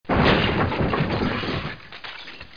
00212_Sound_Bash.mp3